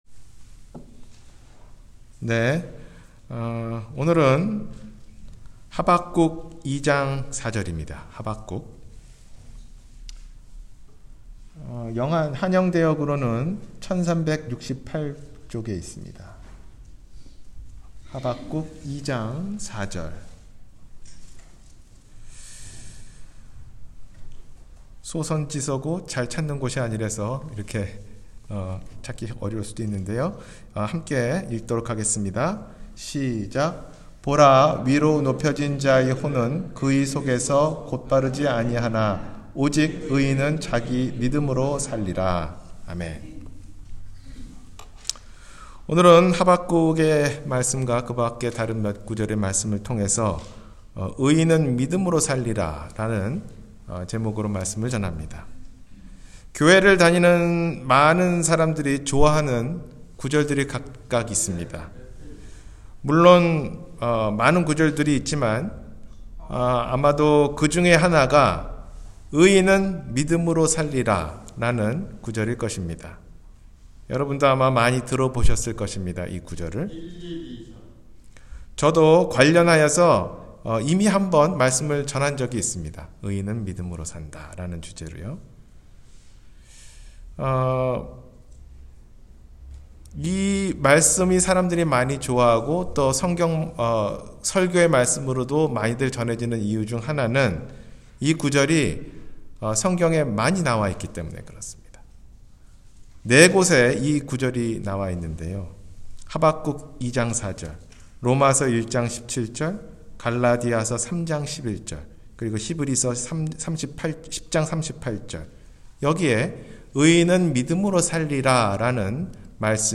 의인은 믿음으로 살리라-주일설교